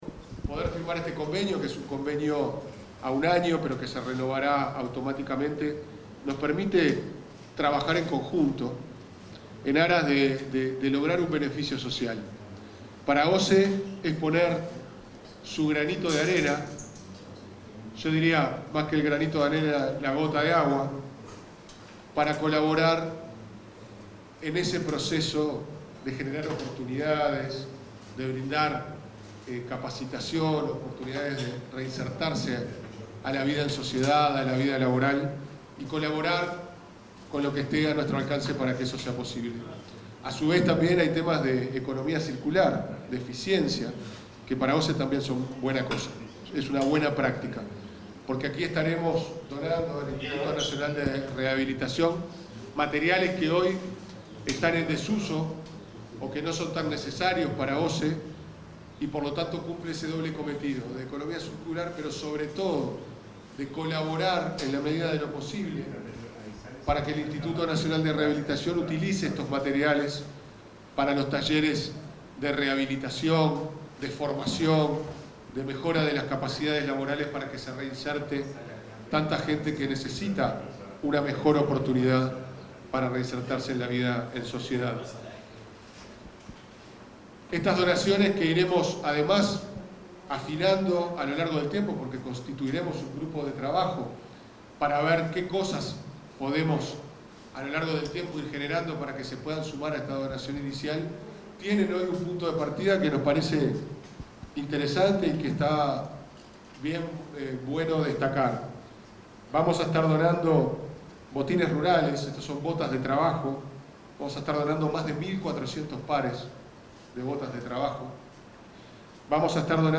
Palabras de autoridades de OSE e INR 05/11/2025 Compartir Facebook X Copiar enlace WhatsApp LinkedIn El presidente de OSE, Pablo Ferreri, y la directora del Instituto Nacional de Rehabilitación, Ana Juanche, se expresaron en la firma del convenio para la donación de insumos.